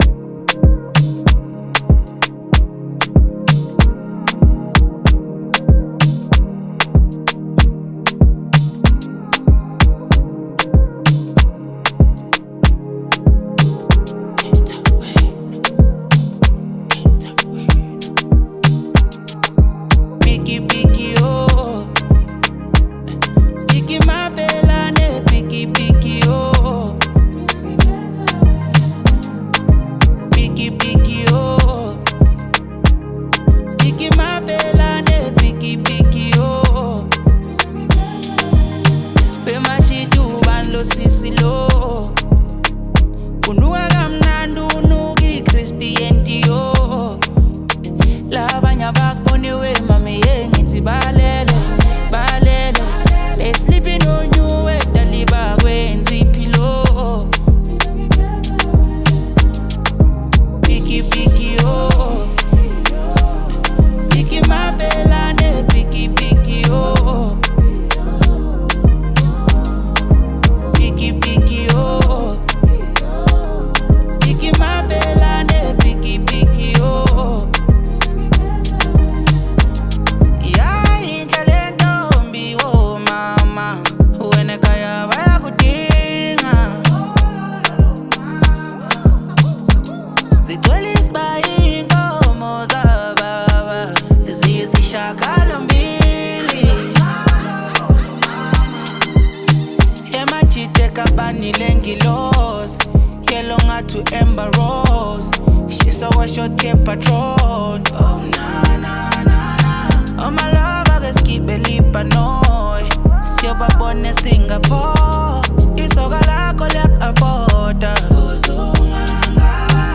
With its upbeat tempo and catchy sounds